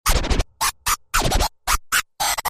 Play Scratching 1 - SoundBoardGuy
Play, download and share Scratching 1 original sound button!!!!
scratching-1.mp3